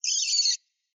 PixelPerfectionCE/assets/minecraft/sounds/mob/rabbit/hurt1.ogg at mc116